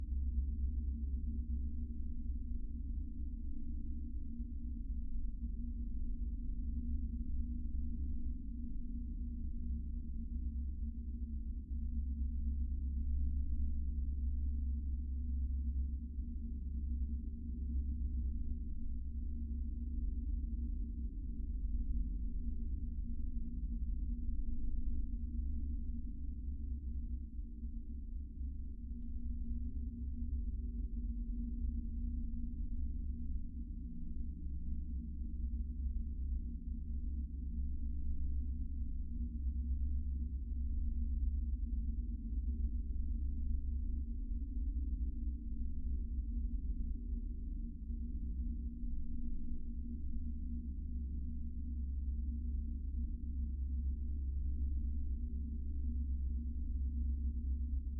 Dungeon_Loop_03.wav